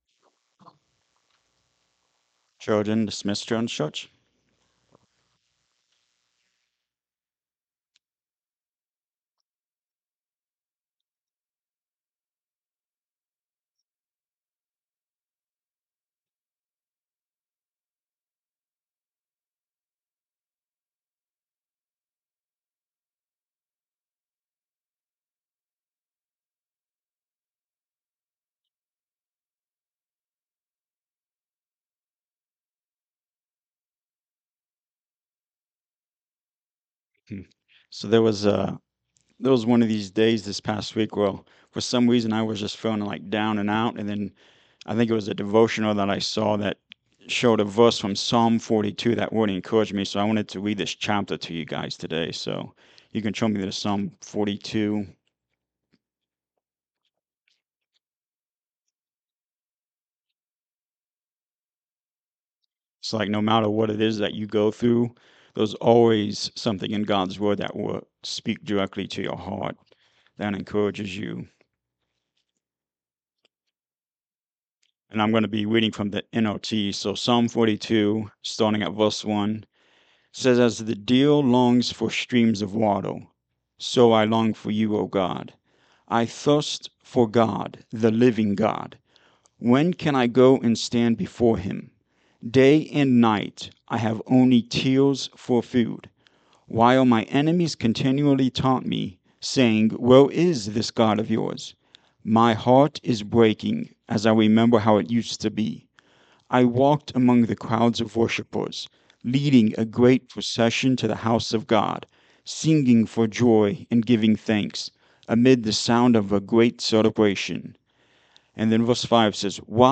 Psalm 42 and God’s Word Series Sermon 6
Psalm 42 Service Type: Sunday Morning Service When the world is discouraging